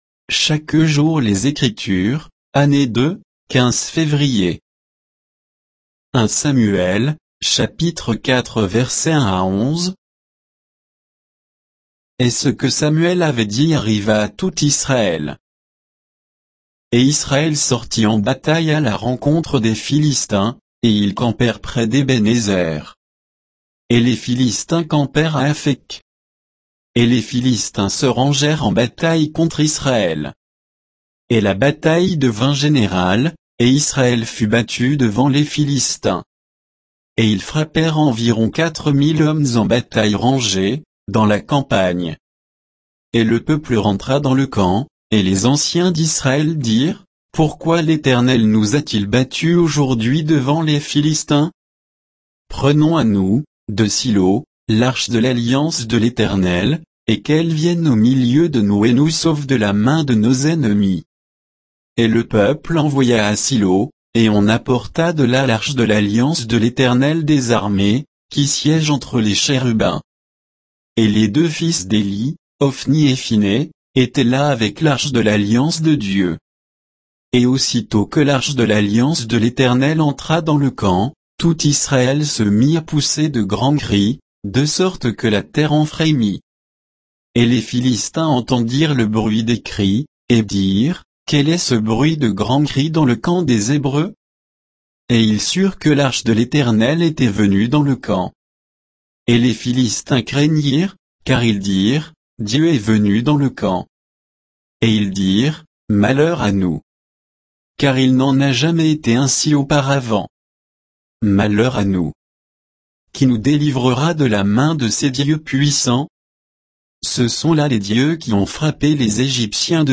Méditation quoditienne de Chaque jour les Écritures sur 1 Samuel 4, 1 à 11